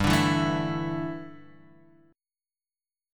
Dsus2/G chord